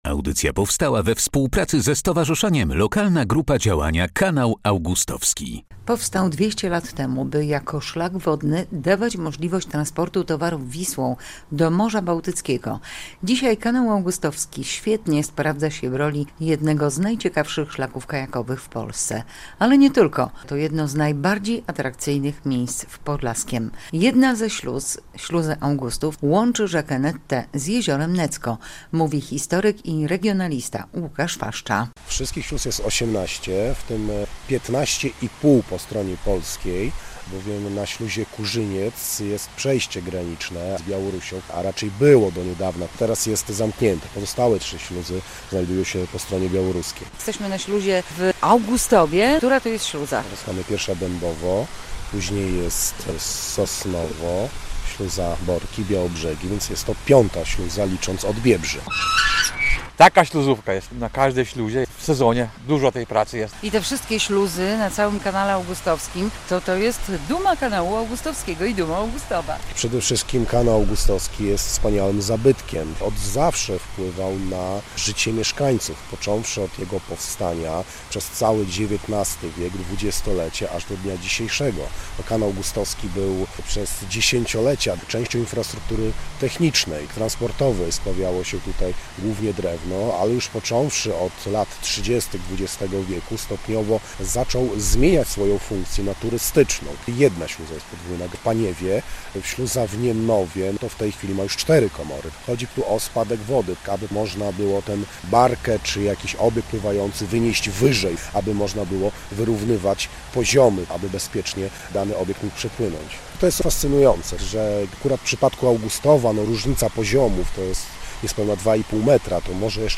relacja